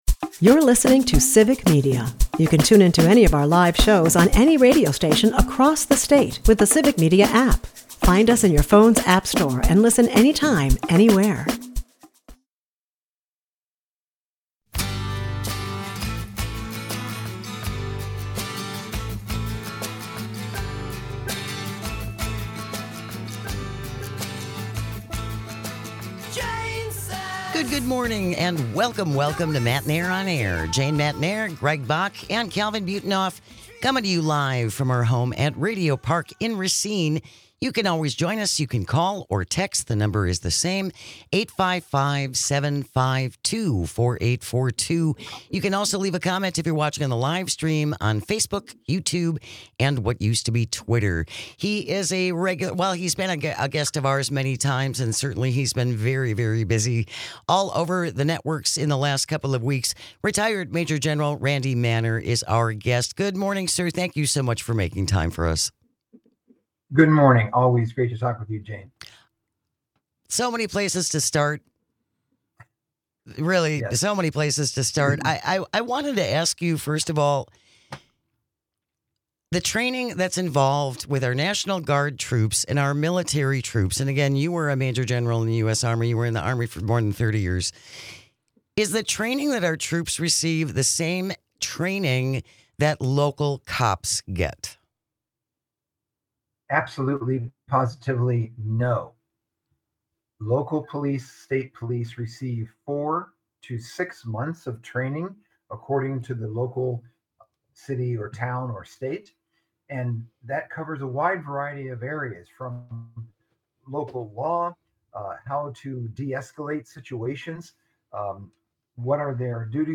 Welcome to the weekend, now enjoy a finely curated show containing some of our favorite moments from the week!